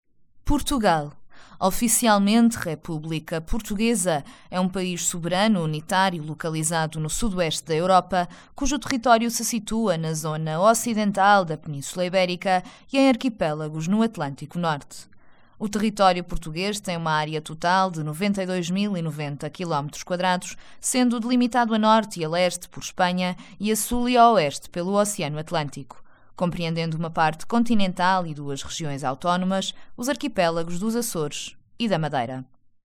✔ My guarantee: I have my own home studio.
Sprechprobe: eLearning (Muttersprache):